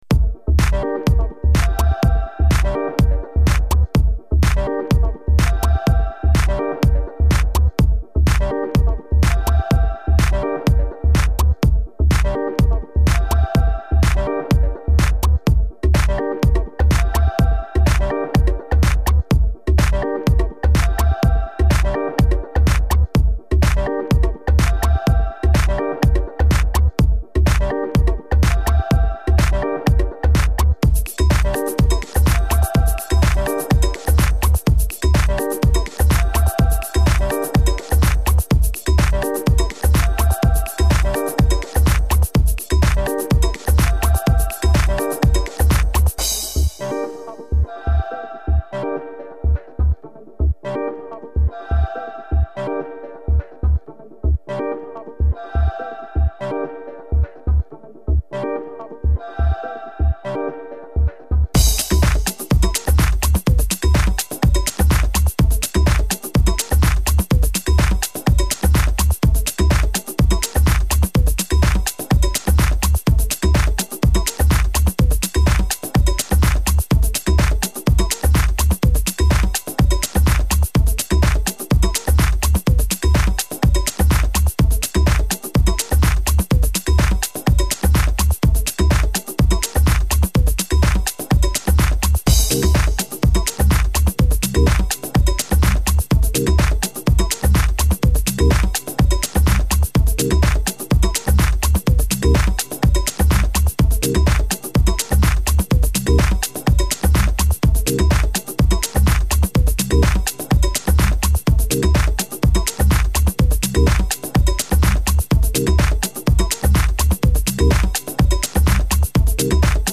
deep house La zona de interacciones sociales de la red musical hispana SONICspace.